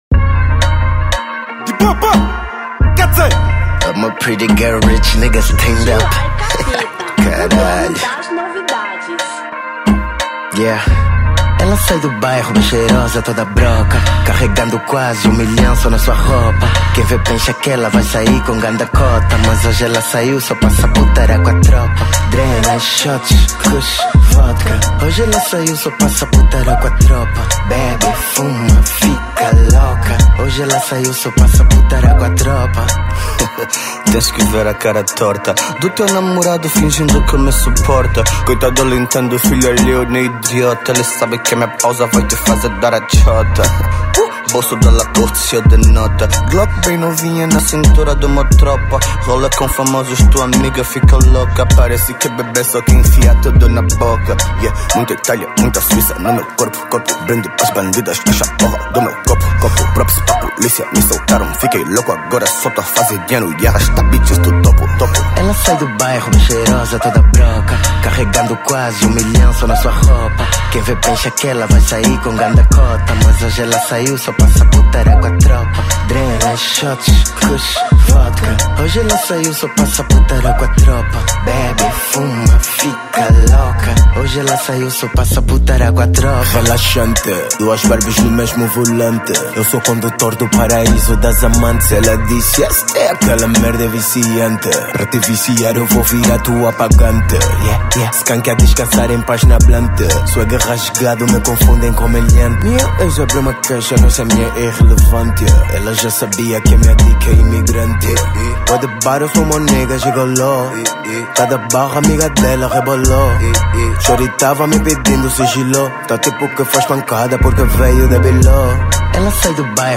Trap Funk